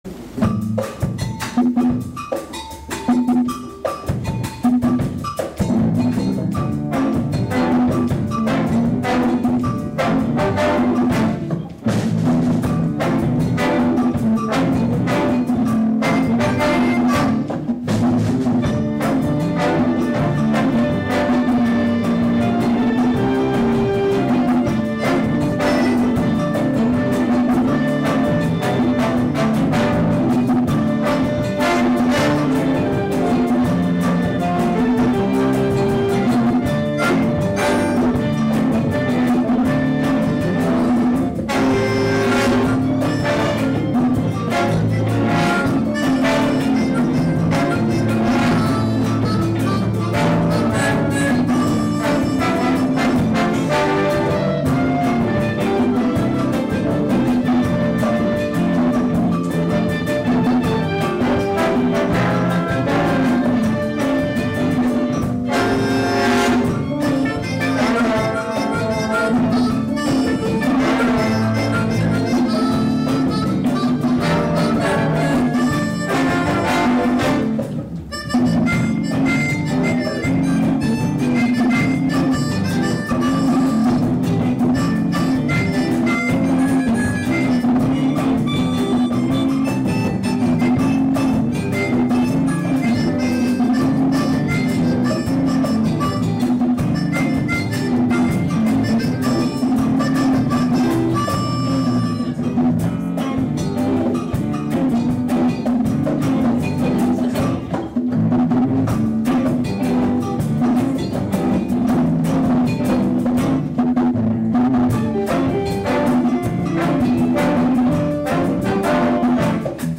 Harmonica Play
Concert